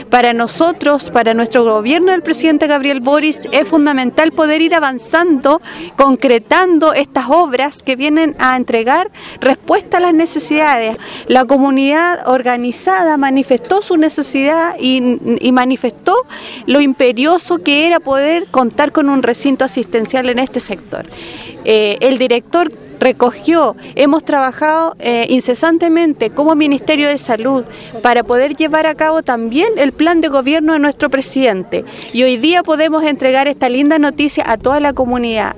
En la oportunidad, la seremi de Salud, Karin Solís, enfatizó el compromiso del gobierno con las necesidades de las comunidades y subrayó el trabajo coordinado con los actores locales para lograr este avance.